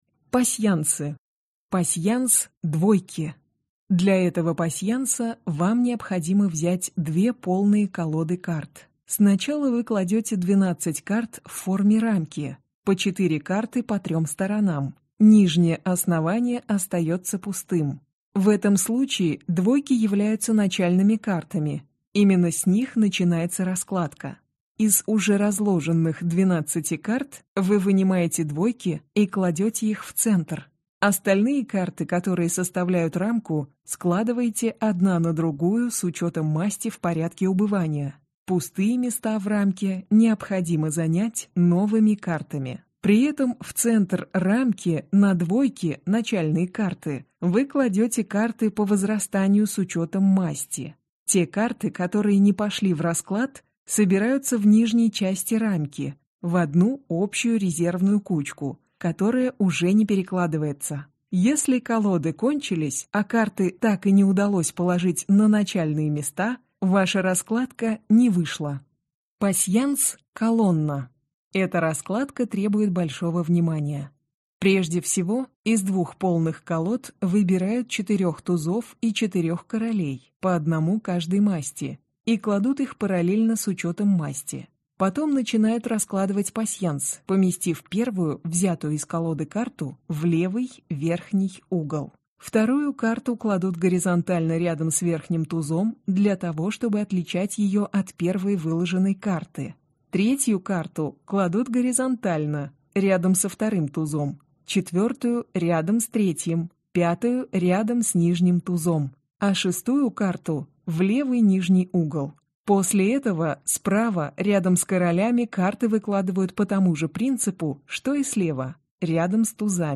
Аудиокнига Книга гаданий | Библиотека аудиокниг
Прослушать и бесплатно скачать фрагмент аудиокниги